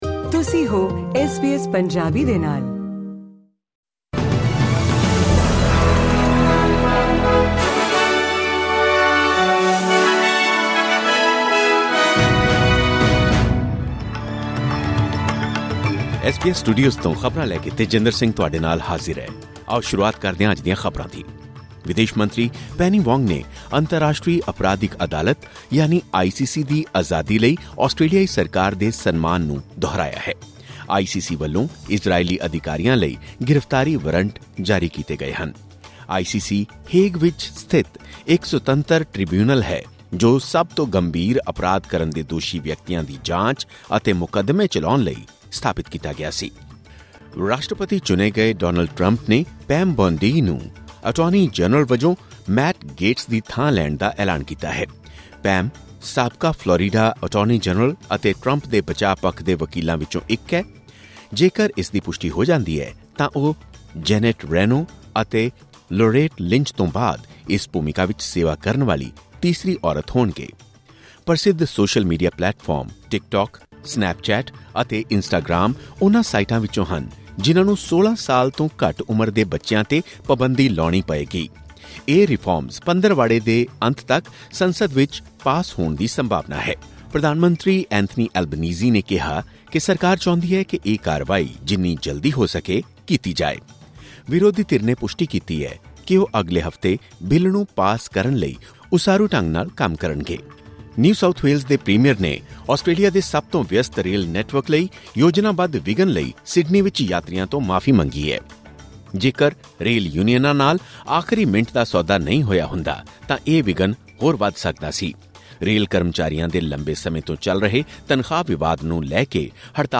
ਐਸ ਬੀ ਐਸ ਪੰਜਾਬੀ ਤੋਂ ਆਸਟ੍ਰੇਲੀਆ ਦੀਆਂ ਮੁੱਖ ਖ਼ਬਰਾਂ: 22 ਨਵੰਬਰ, 2024